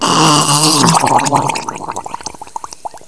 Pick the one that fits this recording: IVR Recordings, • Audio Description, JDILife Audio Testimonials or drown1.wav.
drown1.wav